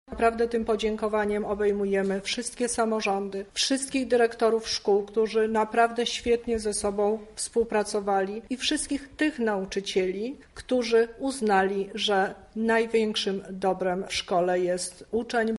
-mówi Teresa Misiuk, Kurator Oświaty w Lublinie.